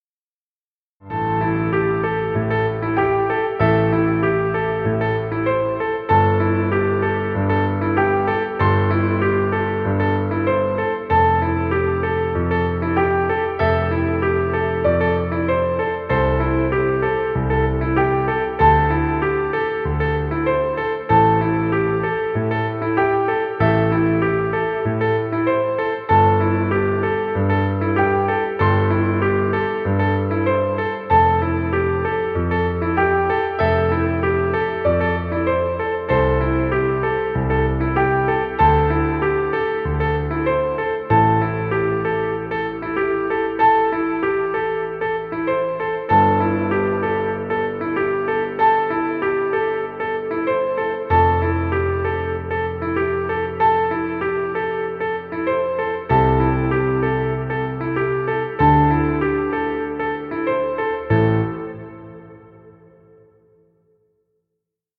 Piano music. Background music Royalty Free.